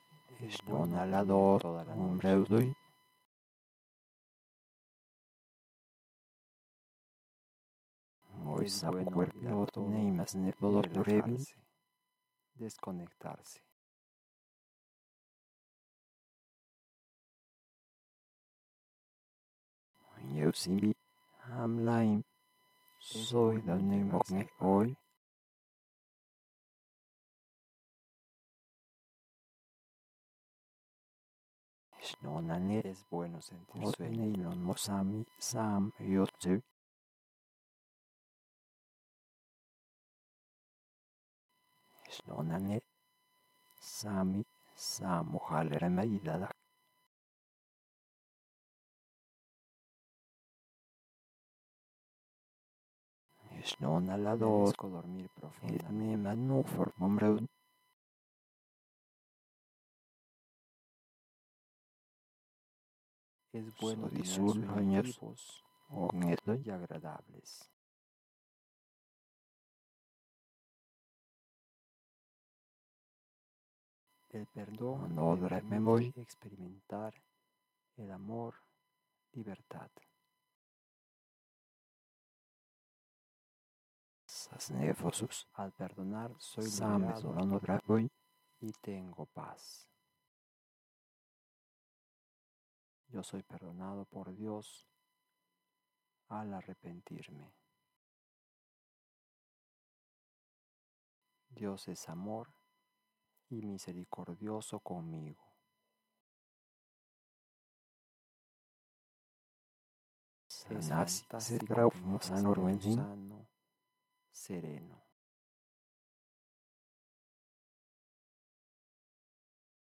Voces solas
dormir_voz_sola.mp3